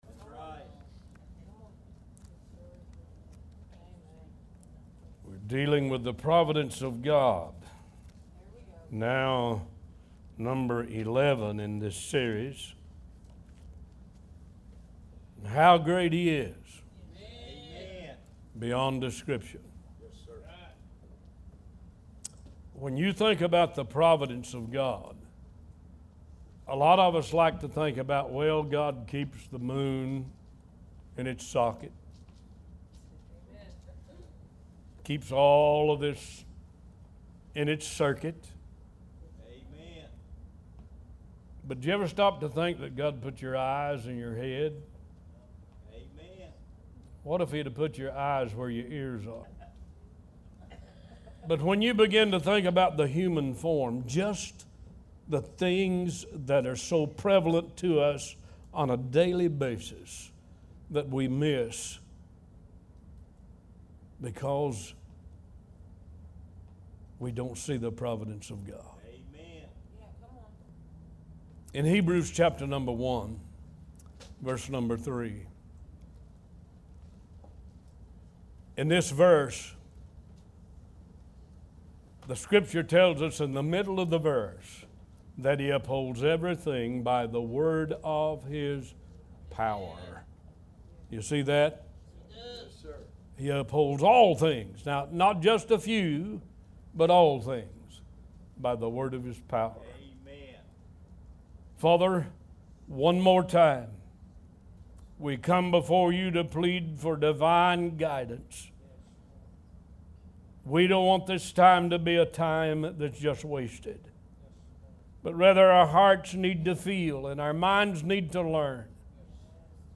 One Voice Talk Show